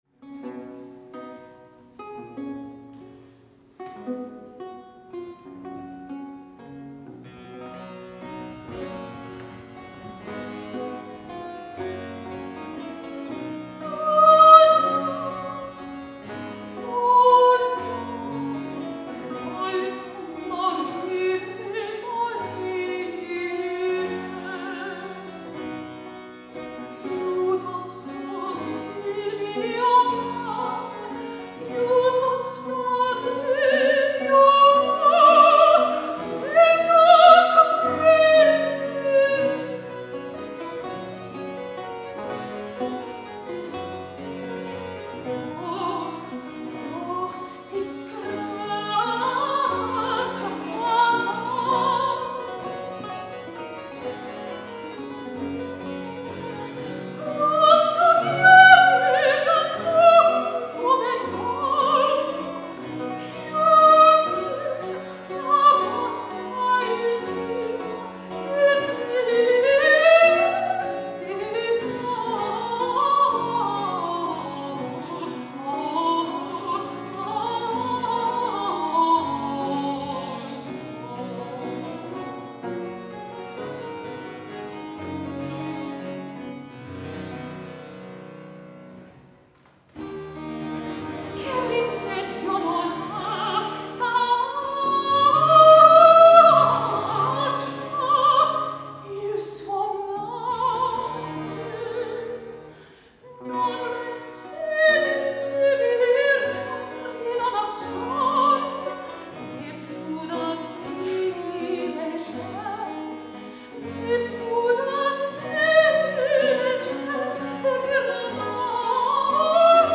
Venue: St. Brendan’s Church
Instrumentation Category:Baroque Ensemble
Instrumentation Other: S-solo, vc, thb, hpd
violin
cello
theorbo
harpsichord
mezzo-soprano
constructed as a mini opera, with formal lyrical passages of vocal variations
over a simple 4-note descending passacaglia broken up by sections of
recitative, during which the emotions are intensely expressed by means of vocal